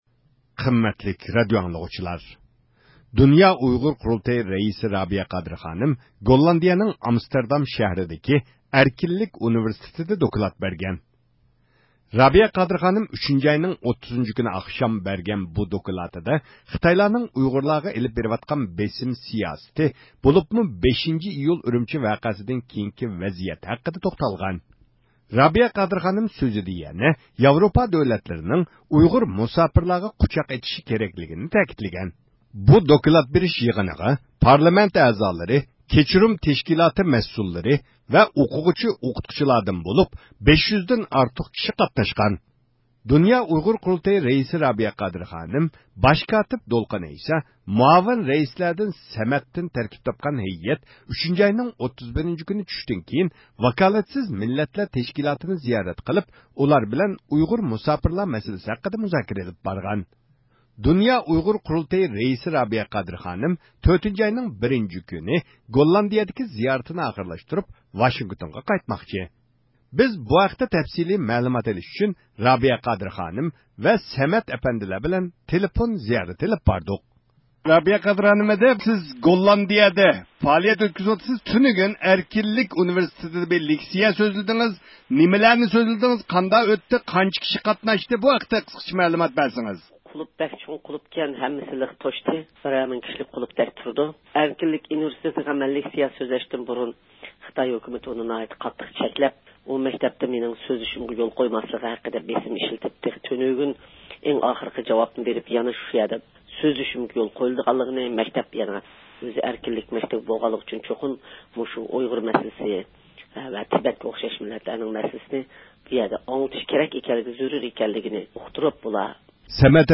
تېلېفون زىيارىتى ئېلىپ باردۇق.